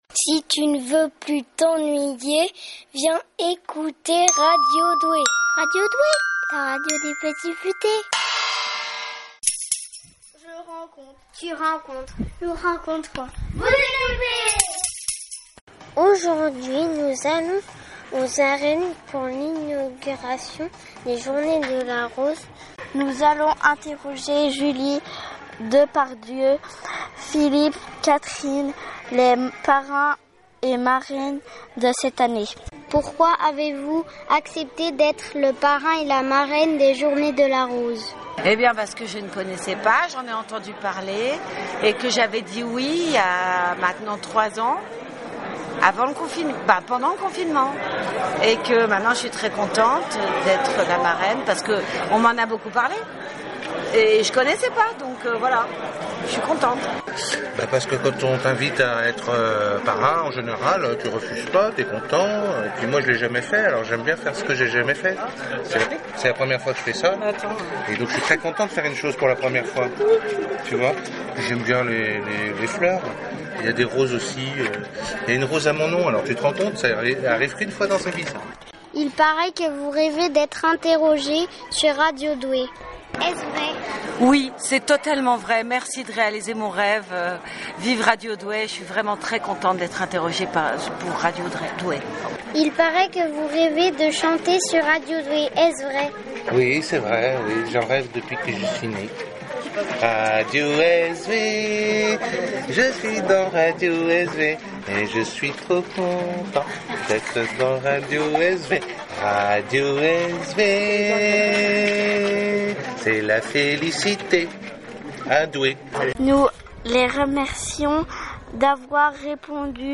julie Depardieu et Philippe Katerine, les parrains des journées de la rose 2022, répondent aux questions de Radio Doué.
Julie-Depardieu-et-Philippe-Katerine-à-linauguration-des-journées-de-la-rose-2022.mp3